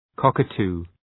Προφορά
{‘kɒkə,tu:}